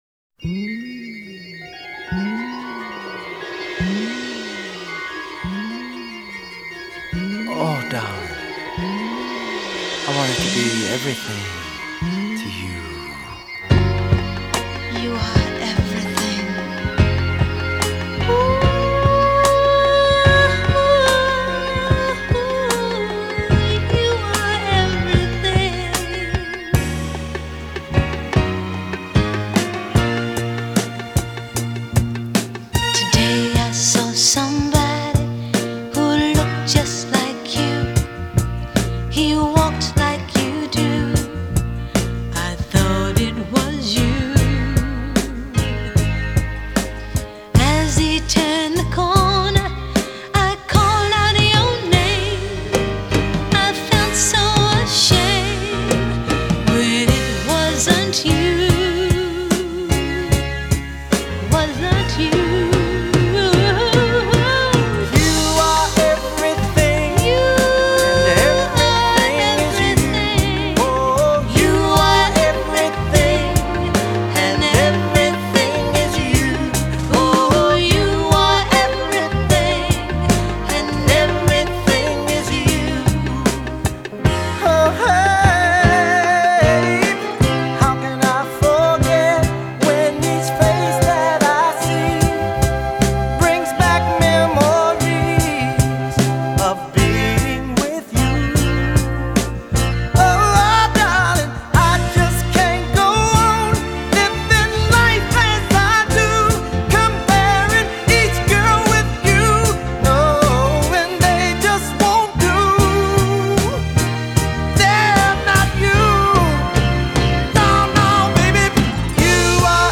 Genre: 70s.